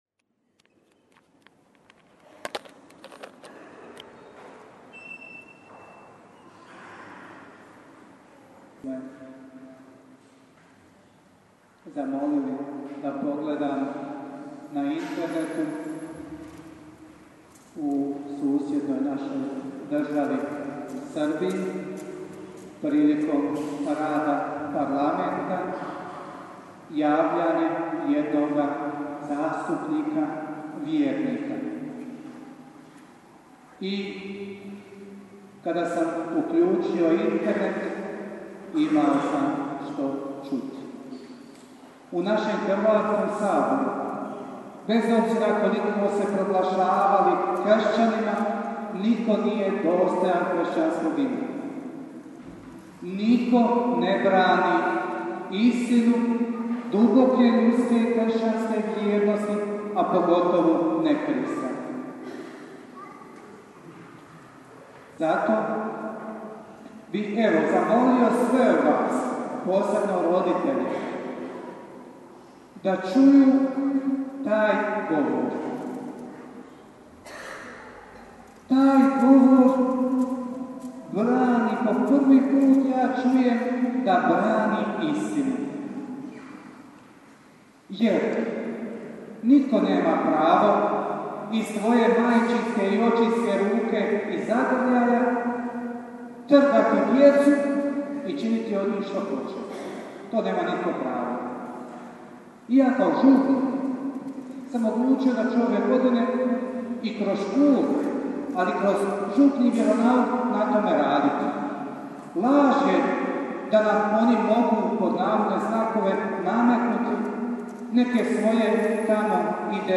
PROPOVJED:
TUMAČENJE EVANĐELJA